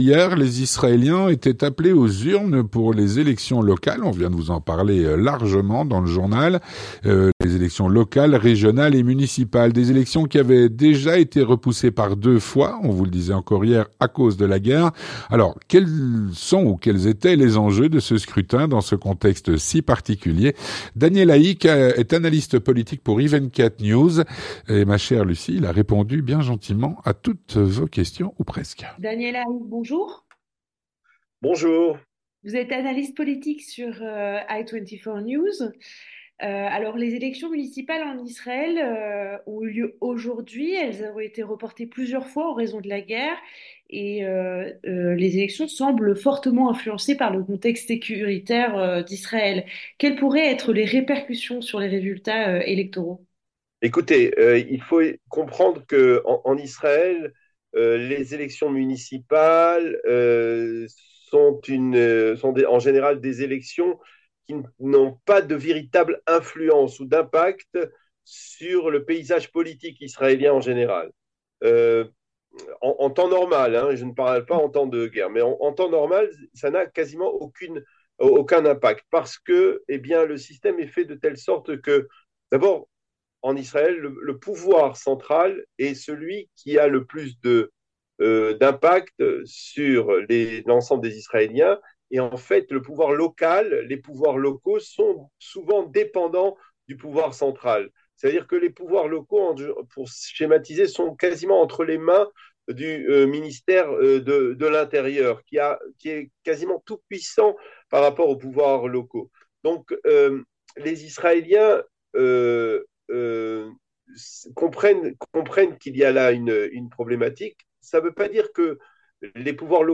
L'entretien du 18H - Hier, les israéliens étaient appelés aux urnes pour les élections locales, régionales et municipales.